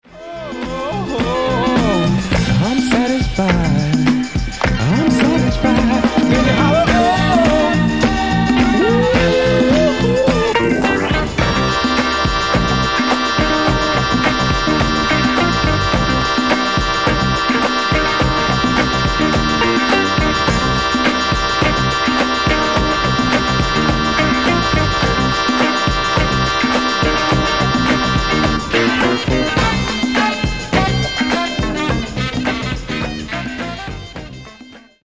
Genere:   Disco | Funky